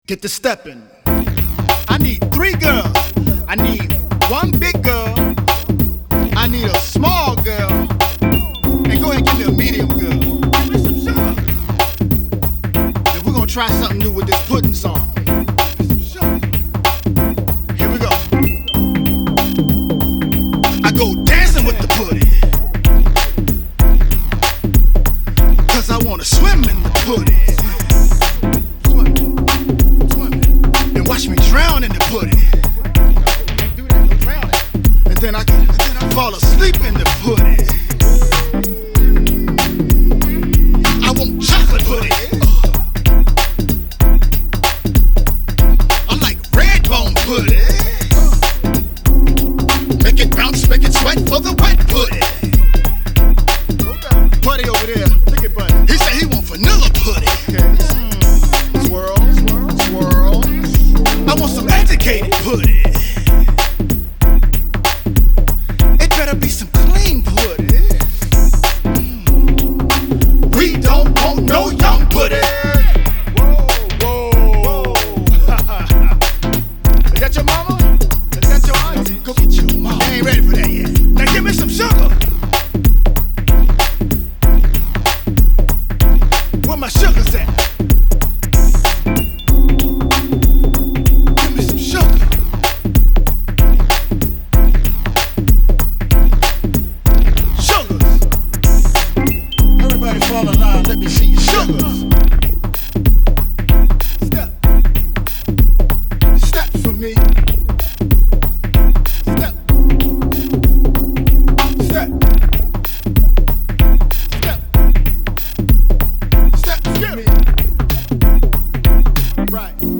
Hiphop
Family & Party Music (i.e southern soul, rap & go-go)